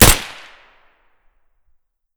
shoot_sil.ogg